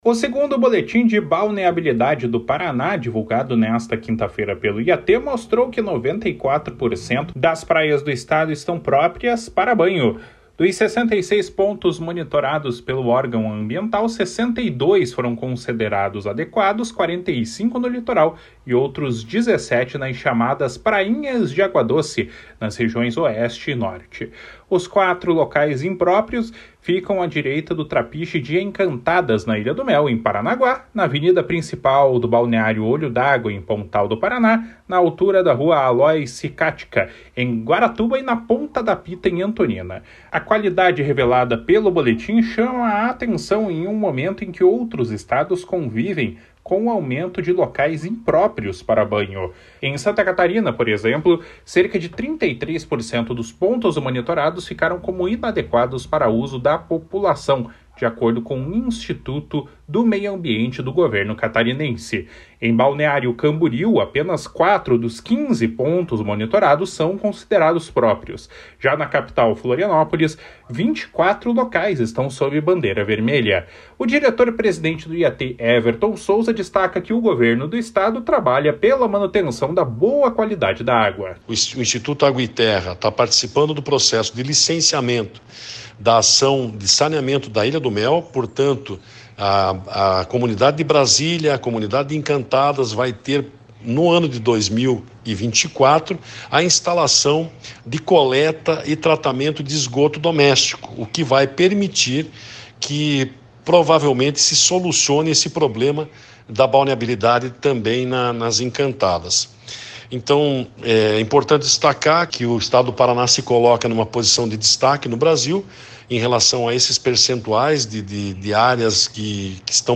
Boletim IAT -28-12.mp3